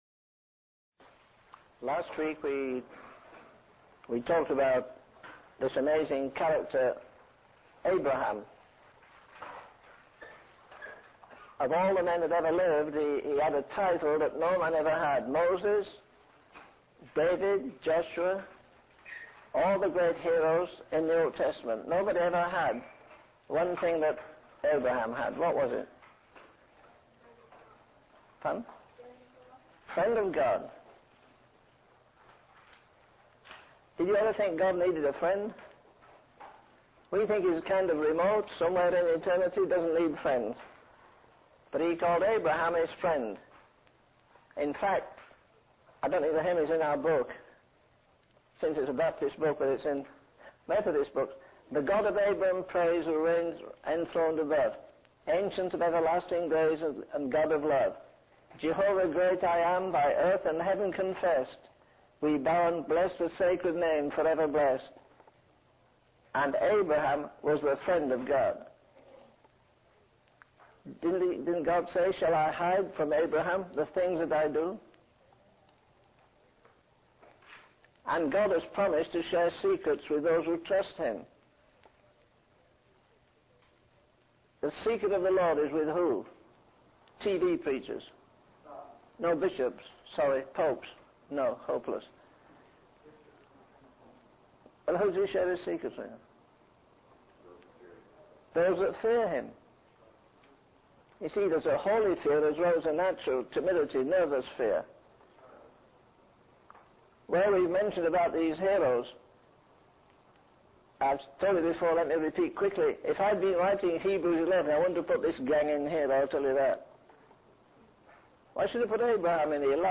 In this sermon, the preacher emphasizes the importance of faith and how it will be tested.